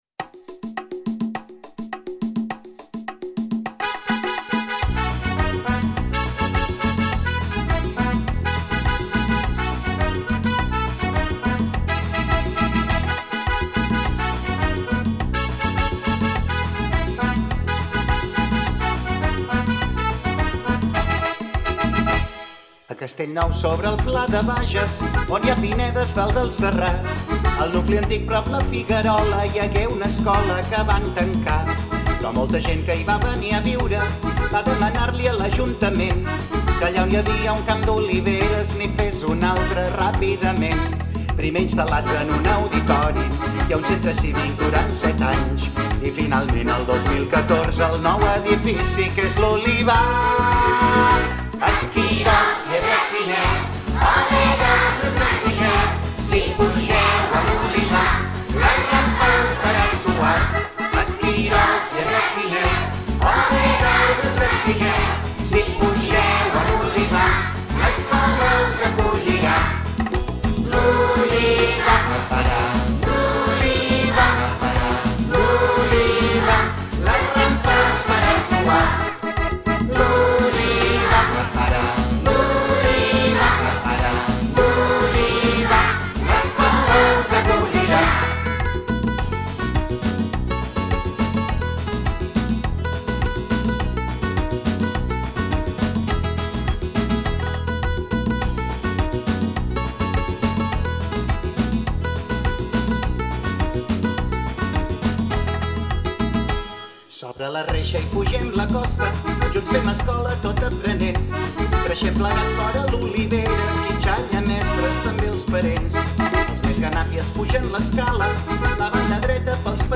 A ritme de Rumba Catalana
Sempre que comença el curs, quan acaba i també en dies assenyalats i especials, a l’Olivar hi sona una rumba ben alegra que ens explica la història de l’escola i que ens convida a ballar i a passar-nos-ho d’allò més bé.
00-ENTRADA-Rumba-de-lOlivar-amb-nens.mp3